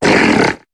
Cri de Monaflèmit dans Pokémon HOME.